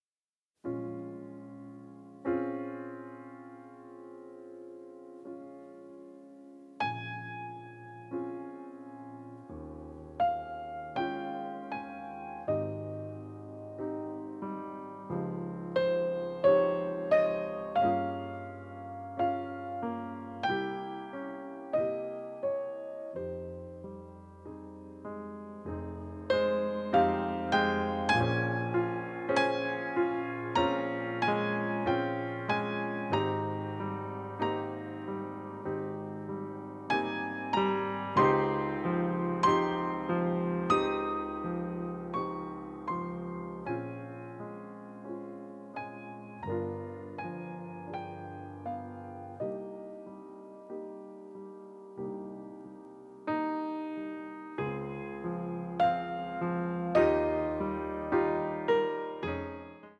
melancholic passages with atonal and dissonant moments